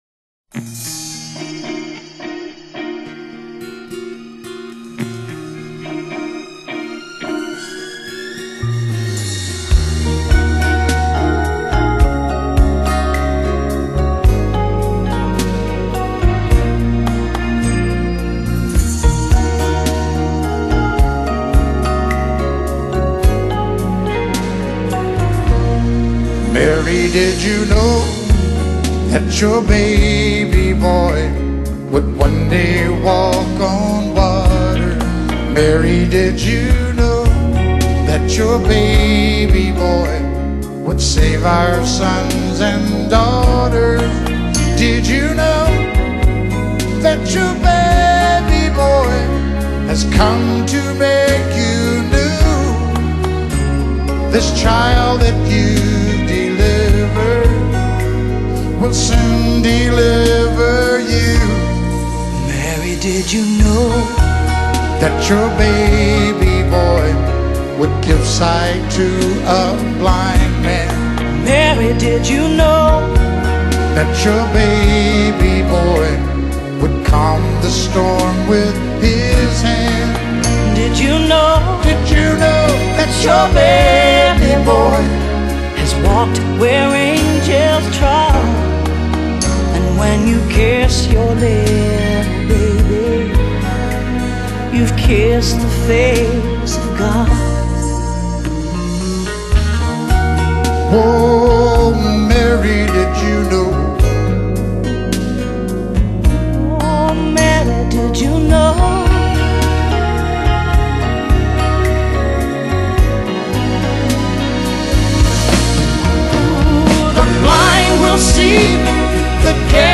Genre:Country